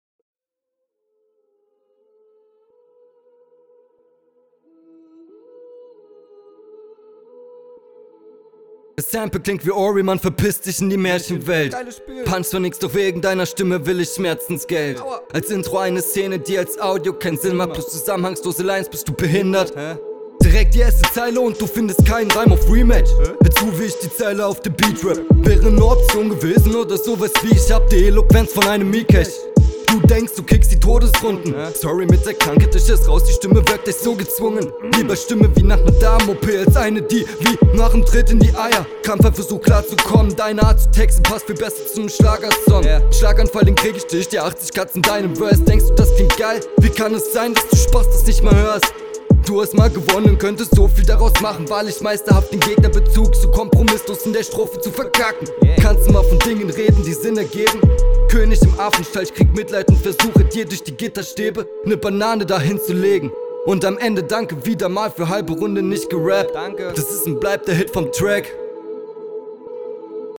Intro ist ganz lustig mit dem Schmerzensgeld, kommst ganz gut auf den Beat.